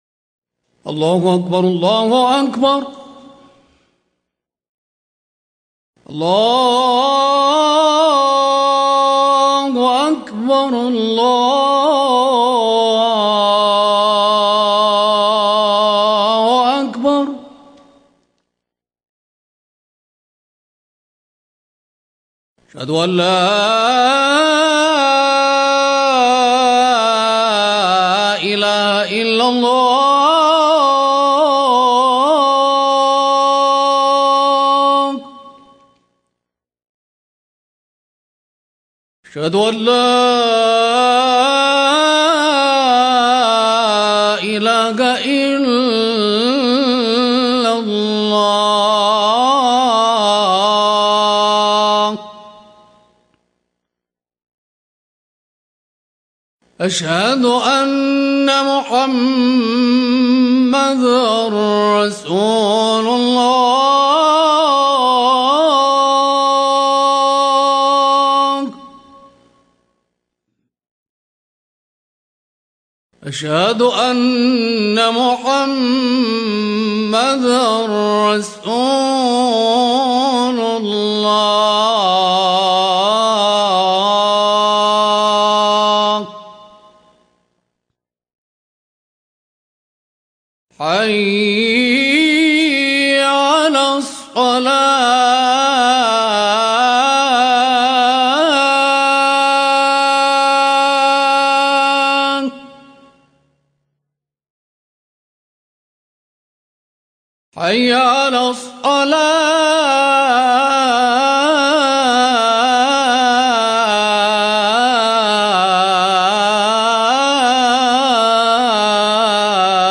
الأذان -بصوت الشيخ
أناشيد ونغمات